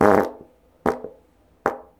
Bum sounds | Sludge Town Library
fart-pop_pop_pop.mp3